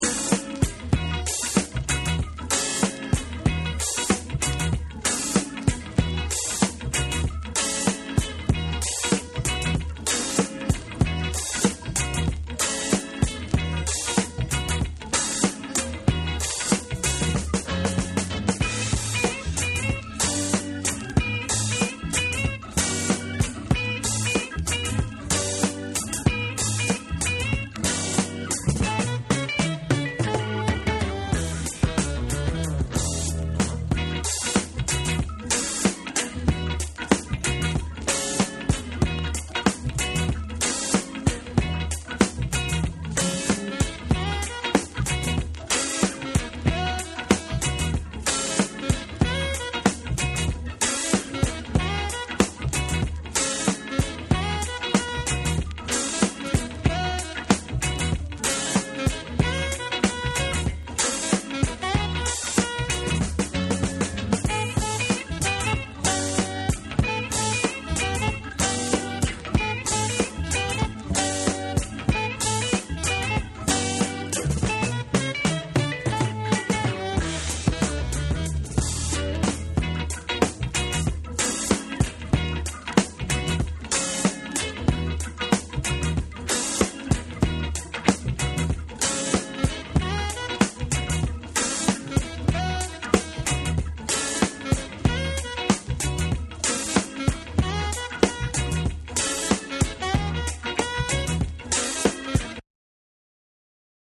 全体をとおして完成度の高いファンキー・チューン